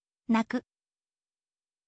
naku